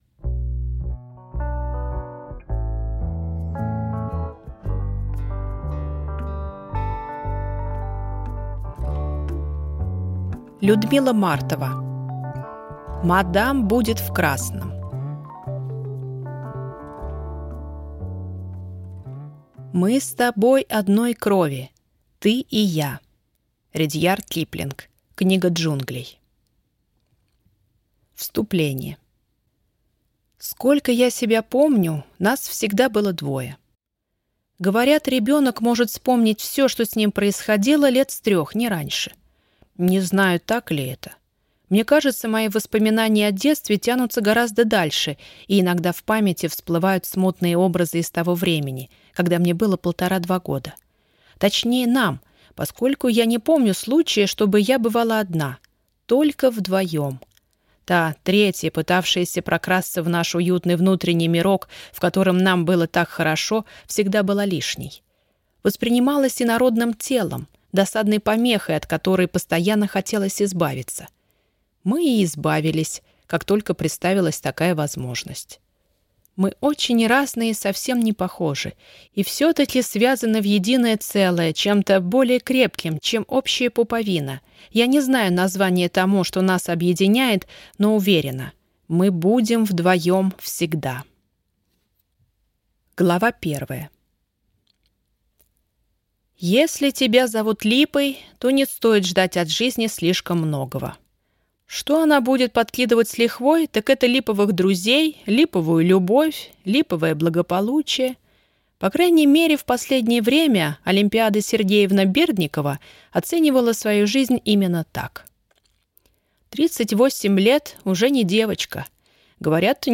Аудиокнига Мадам будет в красном | Библиотека аудиокниг